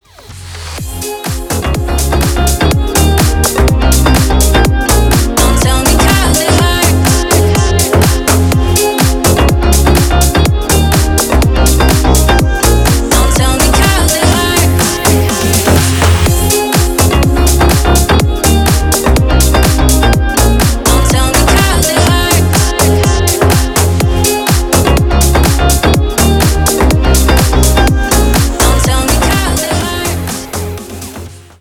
• Качество: 320, Stereo
громкие
remix
Electronic
красивый женский голос
house